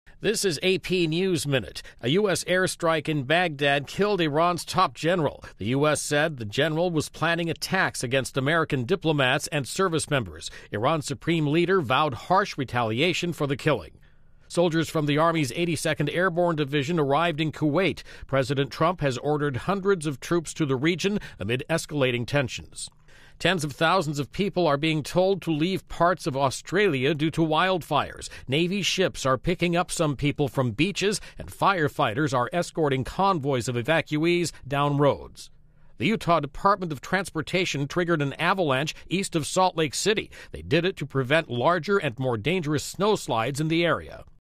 News
美语听力练习素材:伊朗对暗杀行动进行报复